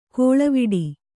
♪ kōḷavoḍi